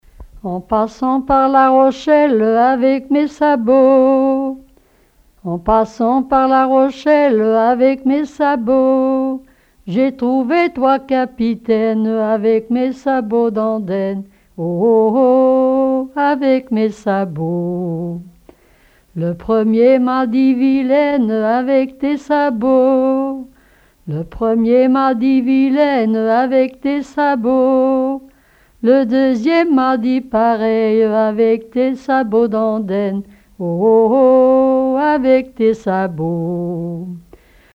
Mémoires et Patrimoines vivants - RaddO est une base de données d'archives iconographiques et sonores.
Témoignages et chansons traditionnelles
Pièce musicale inédite